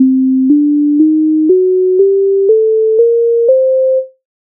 Minor Ukrainian mode
MIDI файл завантажено в тональності c-moll
Standartni_poslidovnosti_C_moll_ukrainian_mode.mp3